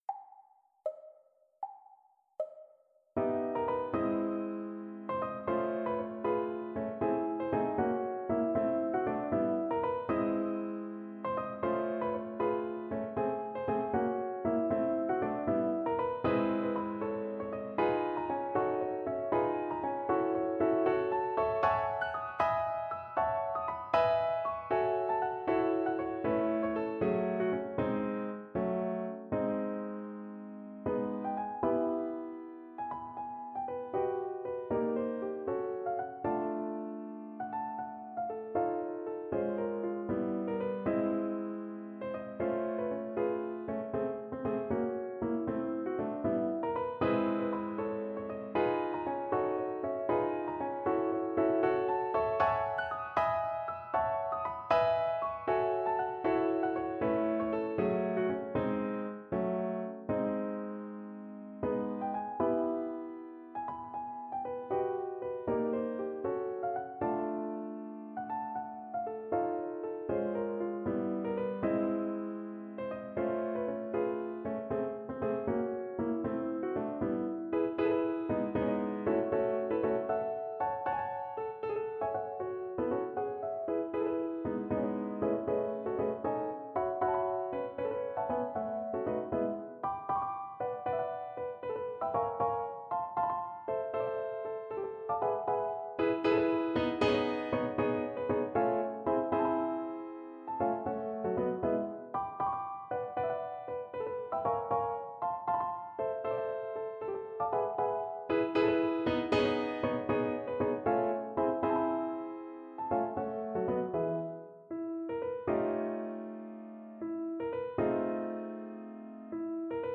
Without Pianist 3